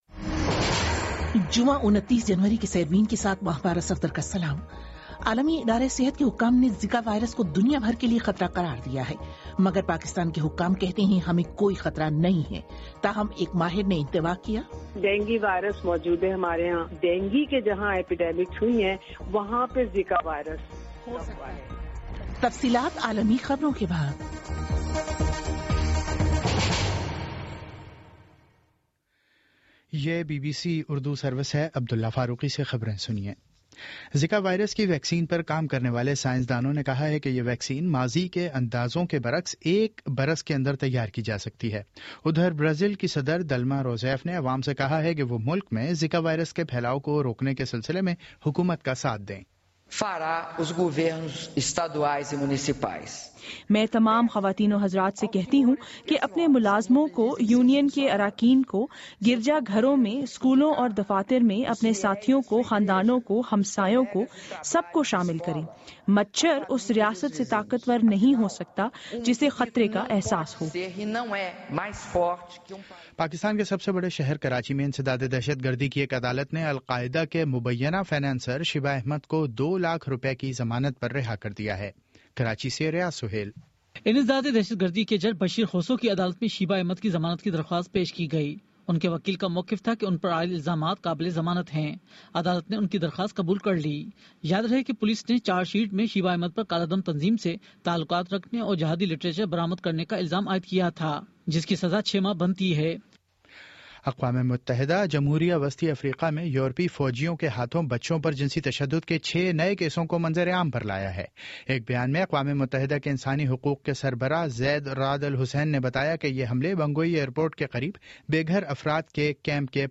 جمعہ 29 جنوری کا سیربین ریڈیو پروگرام